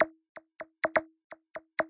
描述：最小的技术性循环126 bpm.
Tag: 最小 126bpm 循环 高科技 TECHNO 蜂鸣